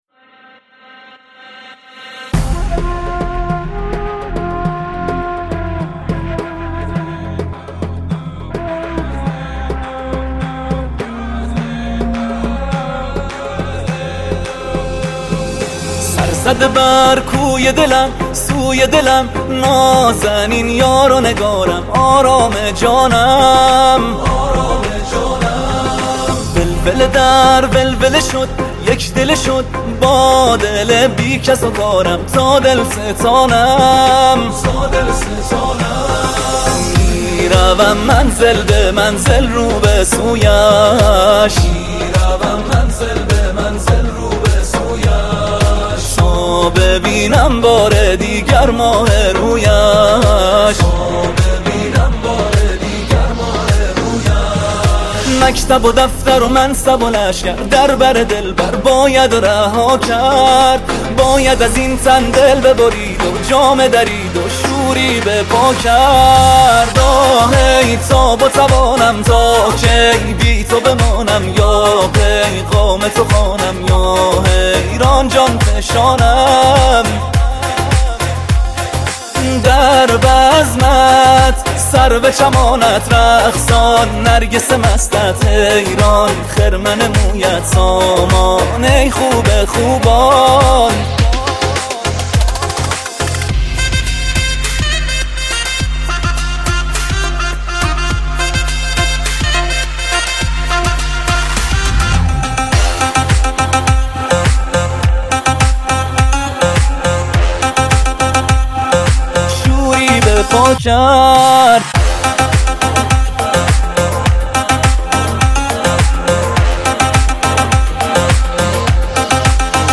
1 آخرین مطالب موسیقی موسیقی پاپ